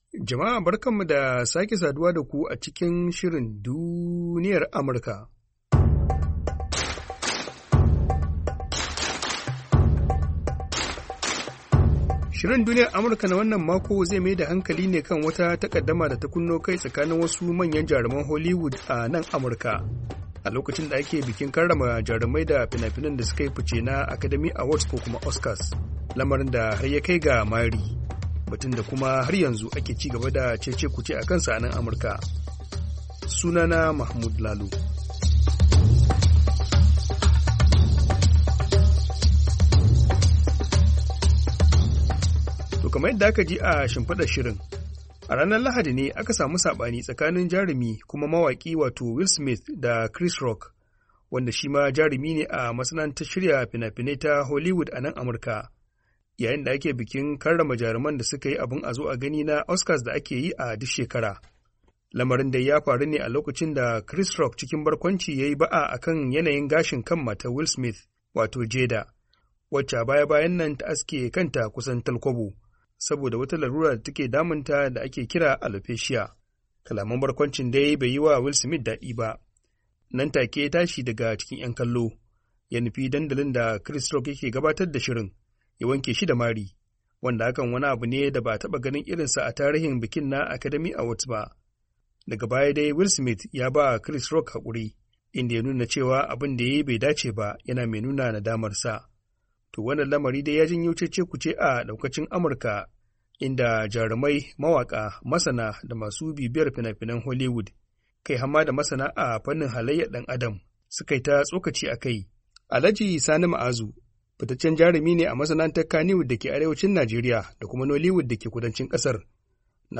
DUNIYAR AMURKA: Tattaunawa